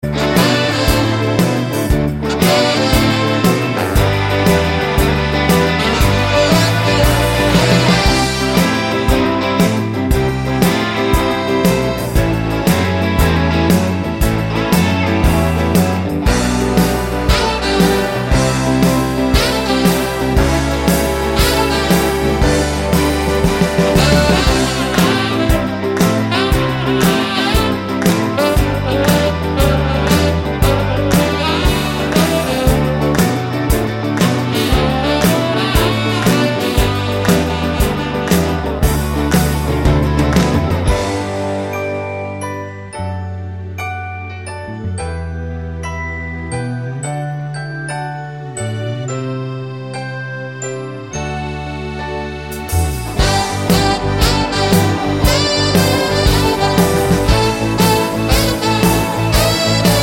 no Backing Vocals Soundtracks 3:17 Buy £1.50